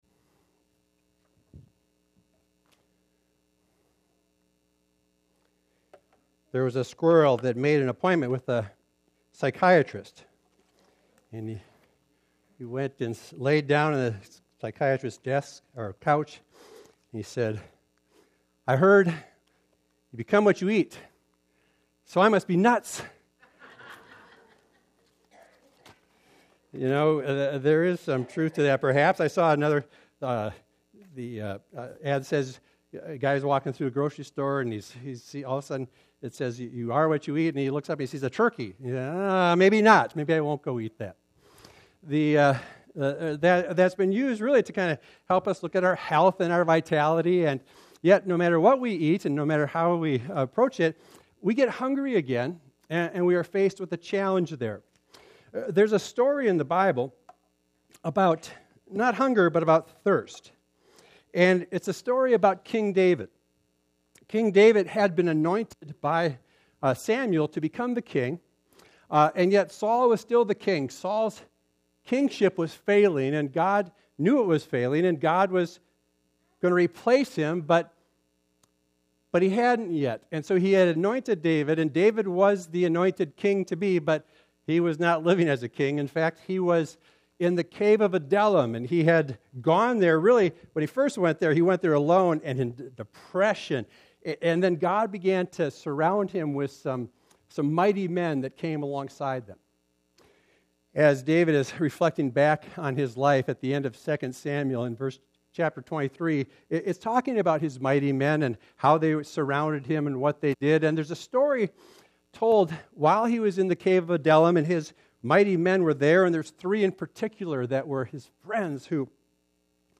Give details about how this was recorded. #5 • Romans 5:6-8; 1 John 2:2; 1 Timothy 2:5-6; 2 Corinthians 5:14-16 Downloads & Resources • Video File • Audio File The MP3 audio file is the radio version of the message recorded for broadcast on WILLIE 105.7 AM, Siren, Wisconsin.